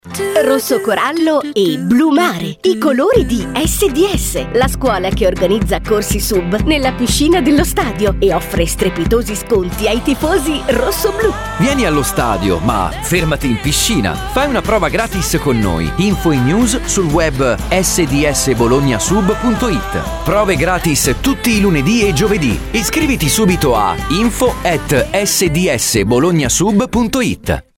Ascolta lo spot pubblicitario di SDS su RADIO INTERNATIONAL